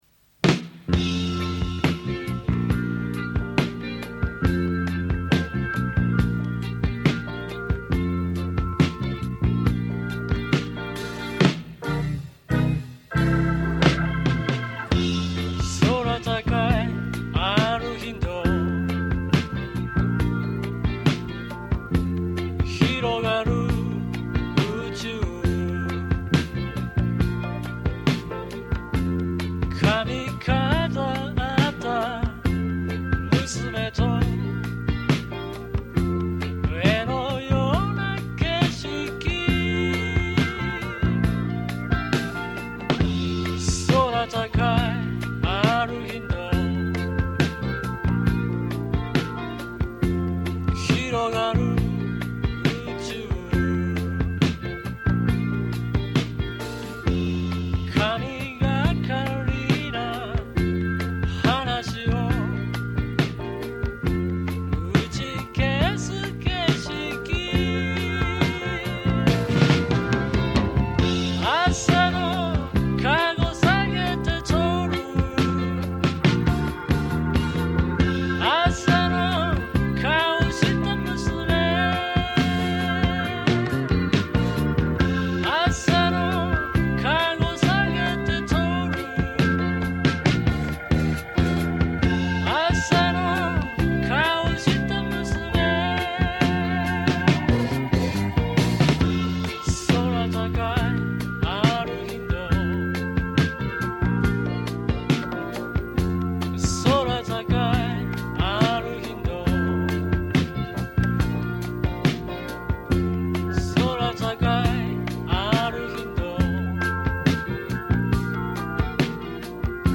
Guitar
Organ